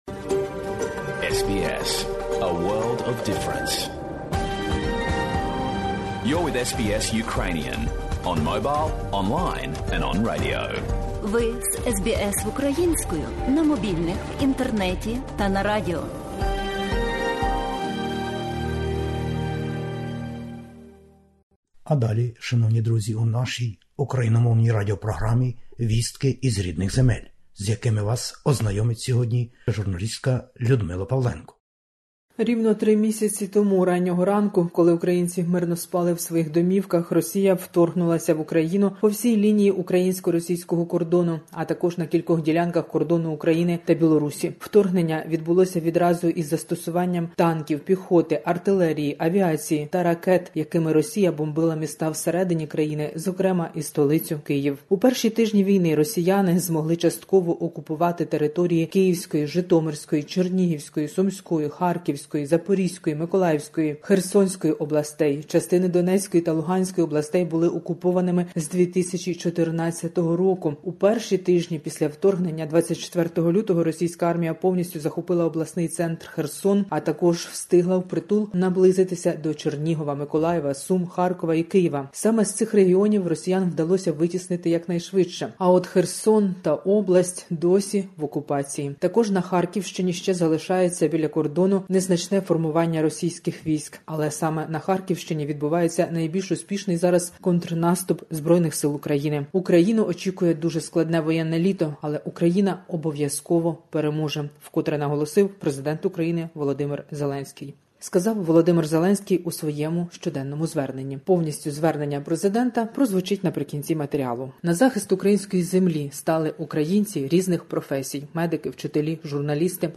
Добірка новин із героїчної України і про Україну. Звернення Президента України. 3 місяці від широкомасштабного російського вторгнення на українські землі.